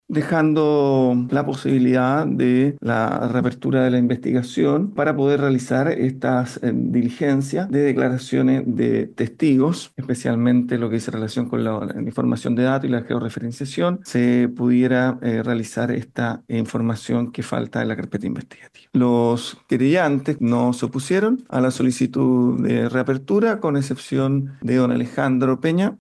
Camilo Obrador, juez del Juzgado de Garantía de Valparaíso, describió la resolución del tribunal, a raíz de la solicitud de dos defensores públicos y la parte querellante.
cu-audiencia-reapertura-megaincendio-juez.mp3